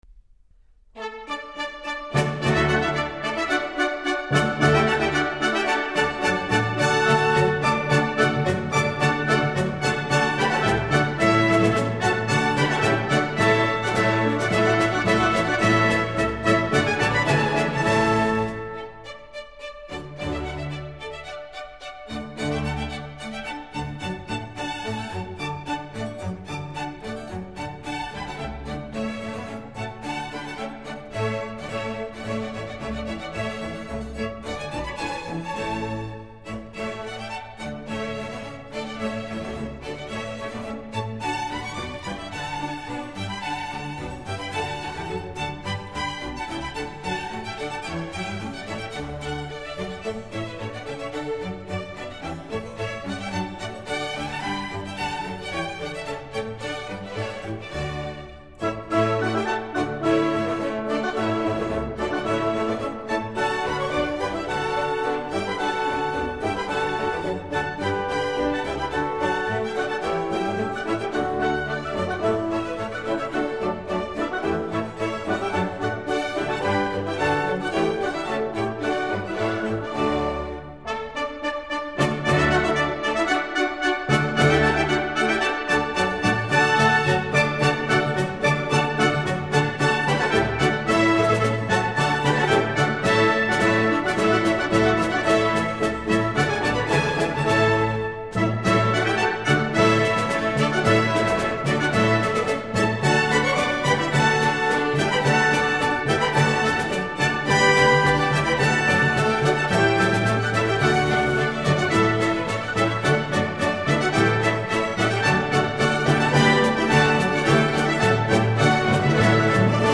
باروک، کلاسیک، رمانتیک